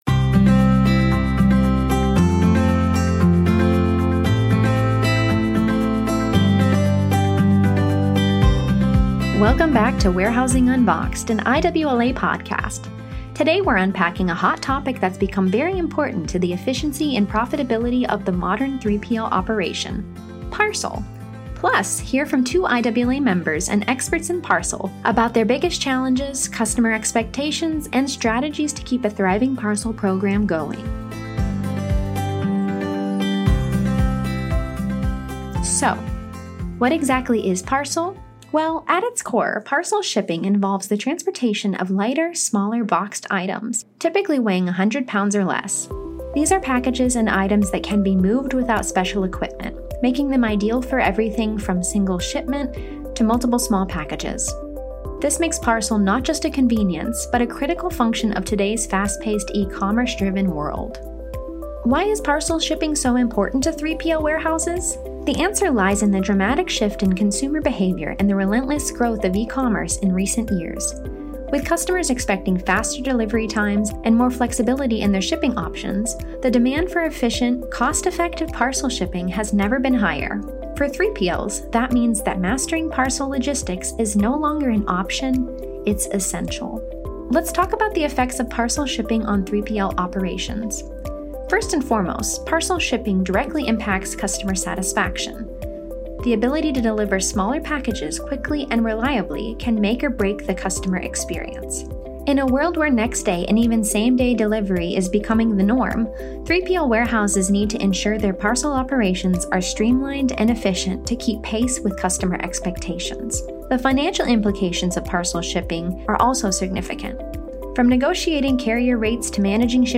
Don't miss this insightful conversation, sponsored by Enveyo.